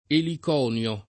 eliconio [ elik 0 n L o ]